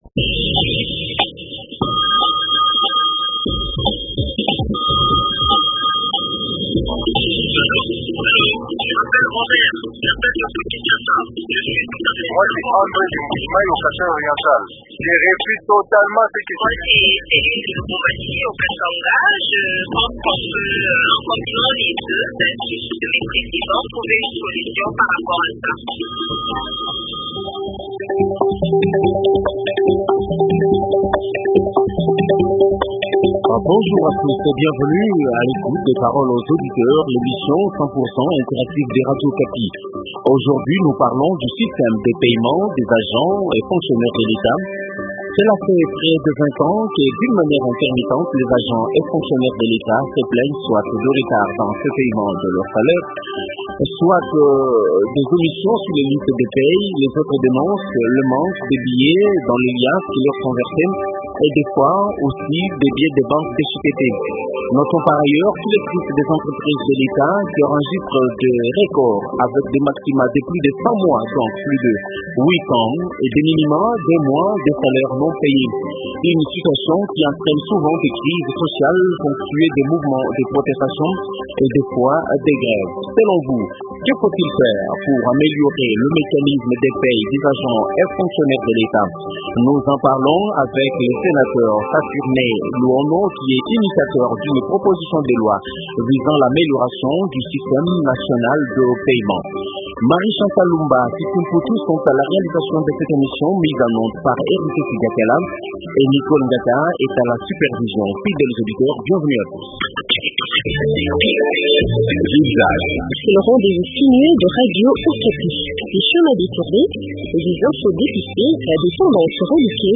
Invité: Saturnin Luono, député national et initiateur d’une proposition de loi visant l’amélioration du système nationale de payement.